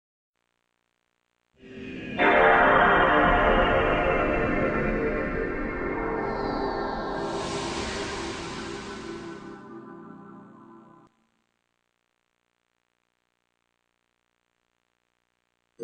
نغمة ميكس ويندوز رائع
Sound Effects